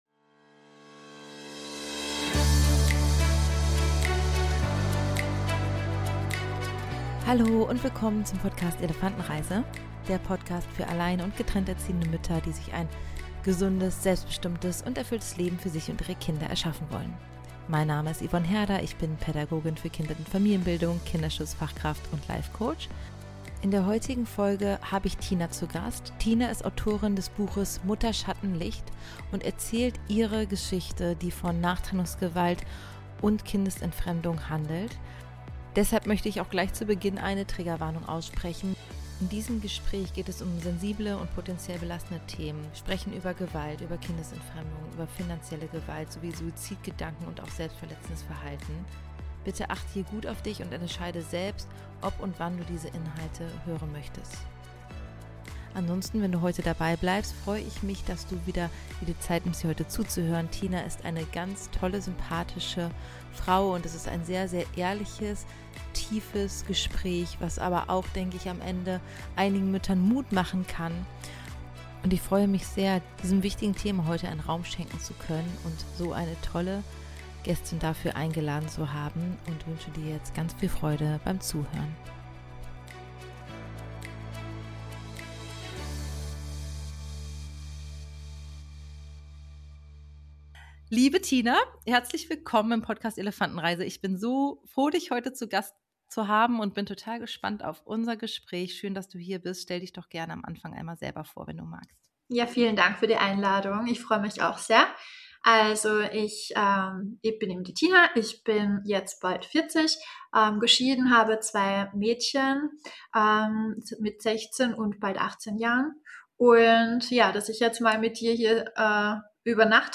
Trotz der Schwere der Themen ist es ein Gespräch, das Mut macht: für alle, die sich in ähnlichen Situationen wiederfinden oder einen Einblick in diese oft unsichtbaren Geschichten bekommen möchten.